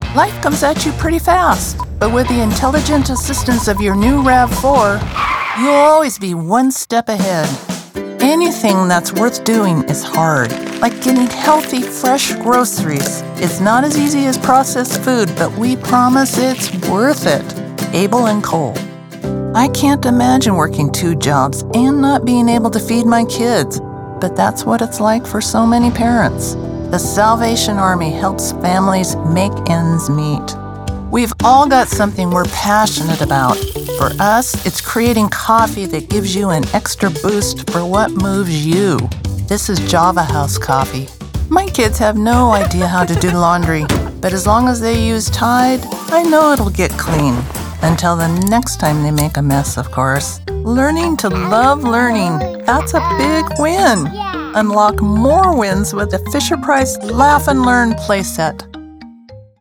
a female voice talent that brings a blend of warmth and expertise to scientific, medical, and financial subjects.